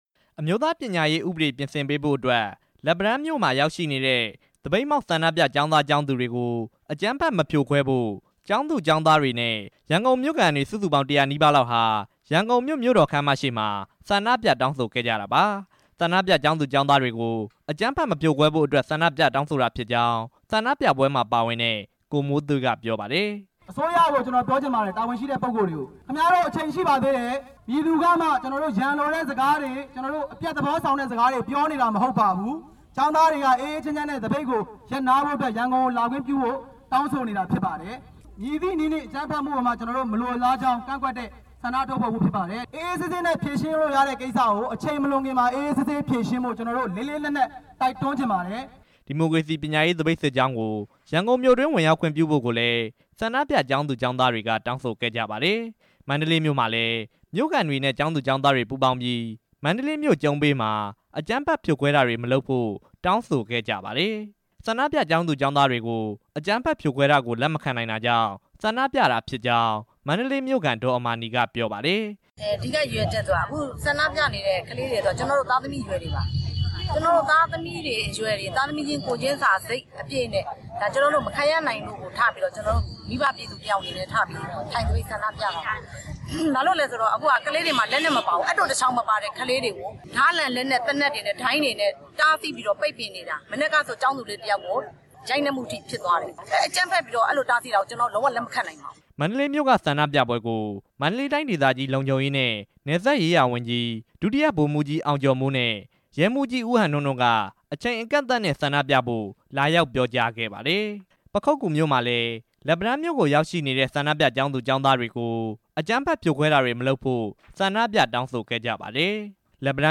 မြို့တချို့မှာ ကျောင်းသားတွေ ဆန္ဒပြတဲ့အကြောင်း တင်ပြချက်